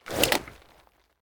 holster1.ogg